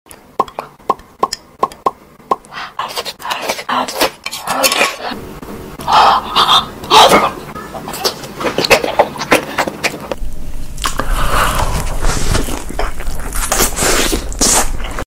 Random color food mukbang Korean sound effects free download
ASMR Testing mukbang Eating Sounds